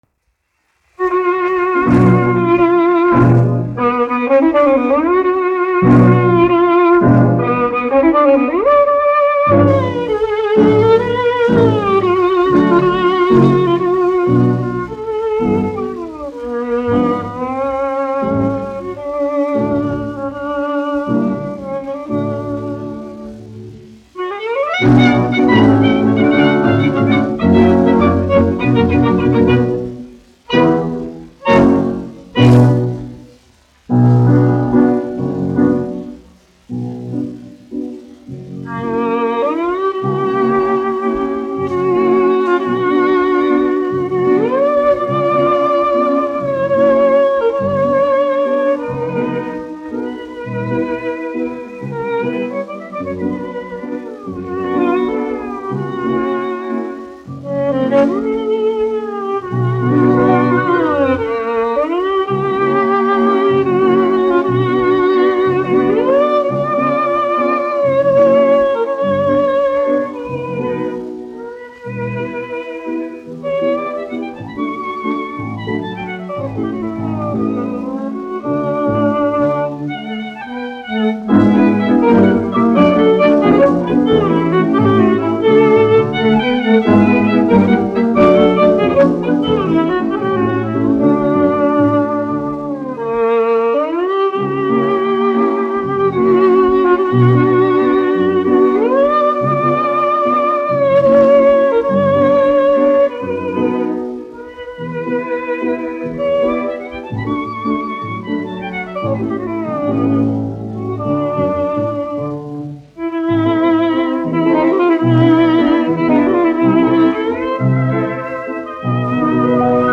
1 skpl. : analogs, 78 apgr/min, mono ; 25 cm
Vijole ar orķestri
Orķestra mūzika
Skaņuplate